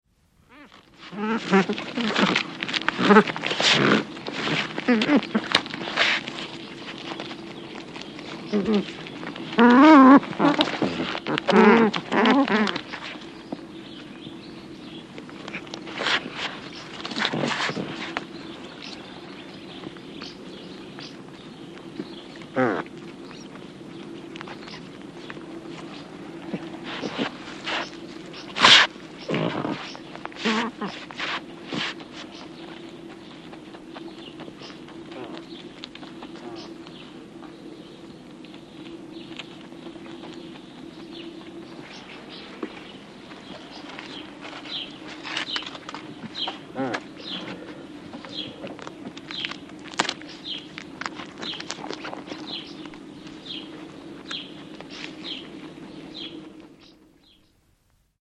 Звук семейства нутрий за трапезой